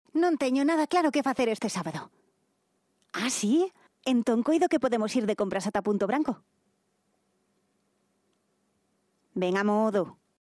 Galician female voice over